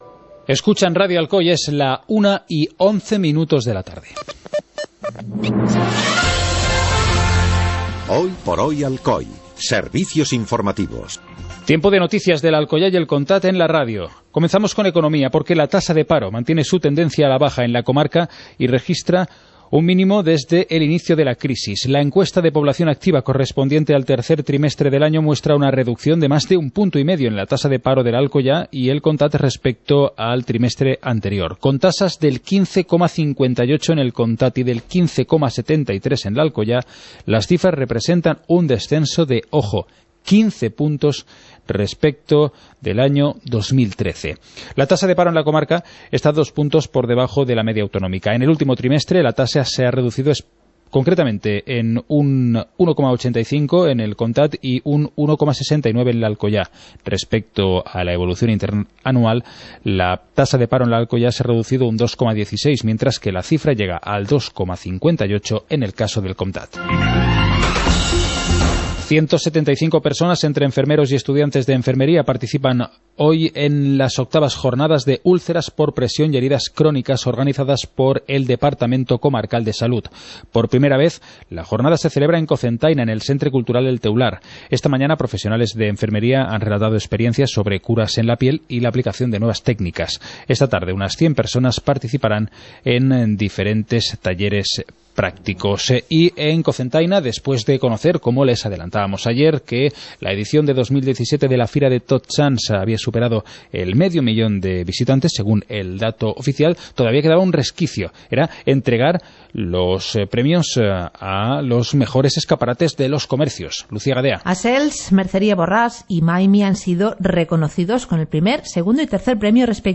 Informativo comarcal - jueves, 16 de noviembre de 2017